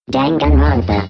Download Danganronpa sound effect for free.